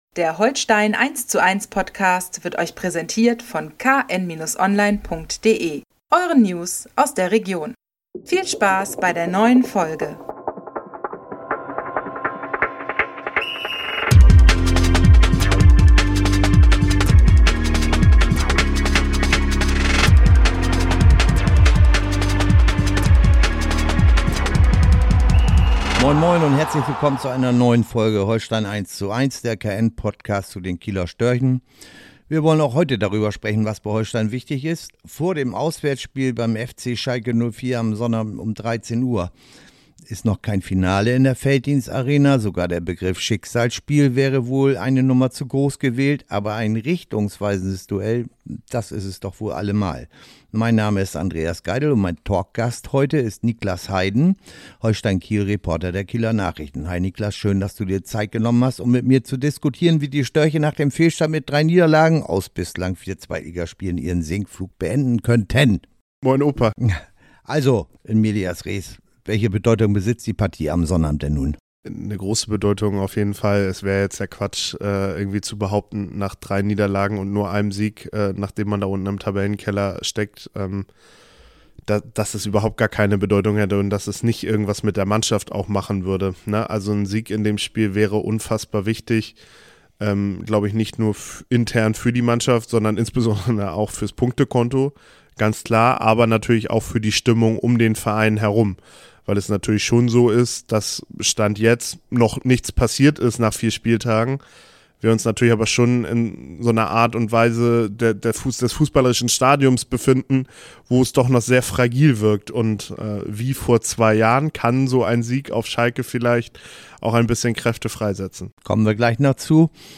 Sach ma Schalke ~ Holstein eins zu eins - Der Fußball Talk Podcast